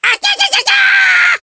One of Diddy Kong's voice clips in Mario Kart Wii